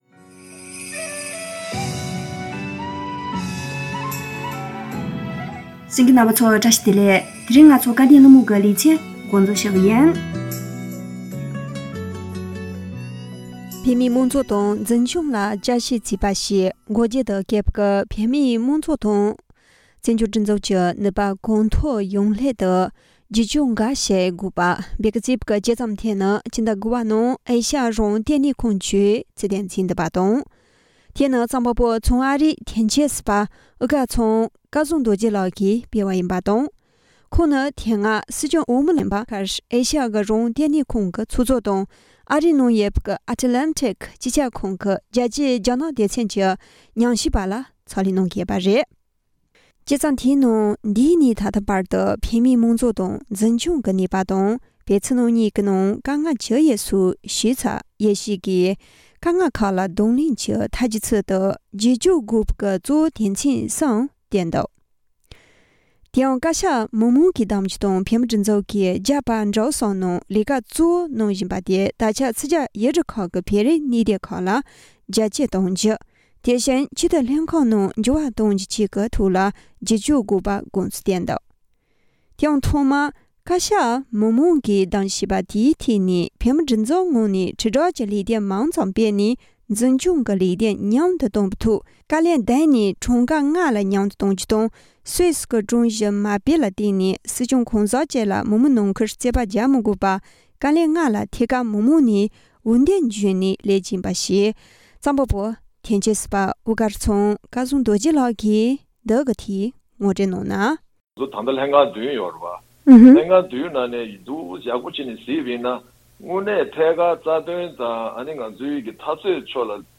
སྐབས་དོན་གླེང་མོལ་